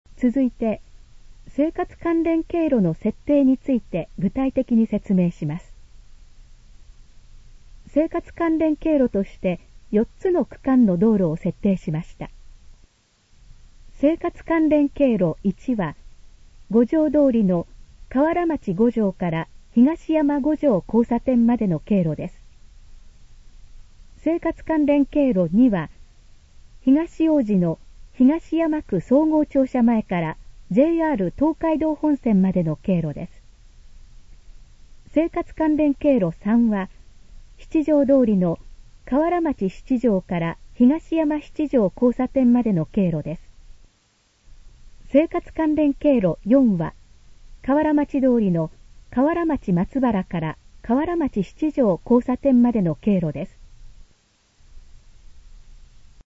以下の項目の要約を音声で読み上げます。
ナレーション再生 約220KB